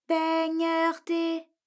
ALYS-DB-003-FRA - Source files of ALYS’ first publicly available French vocal library, initially made for Alter/Ego.